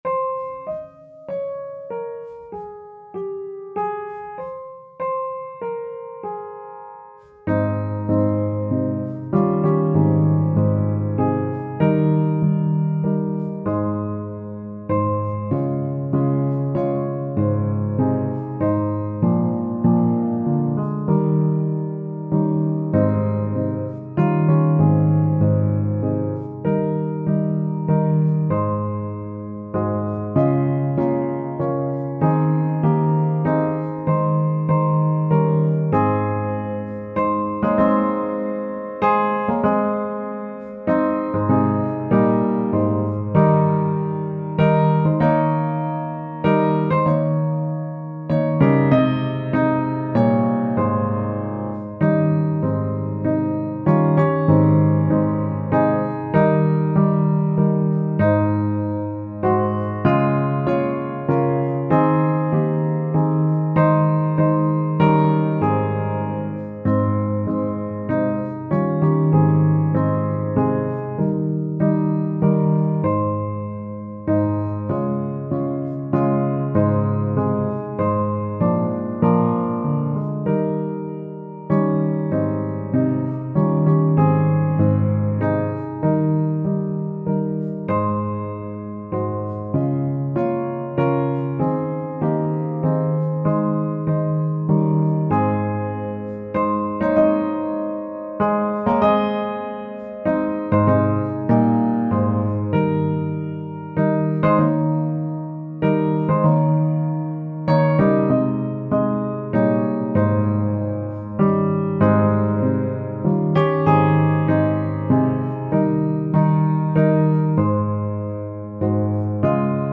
Tempo: Normaal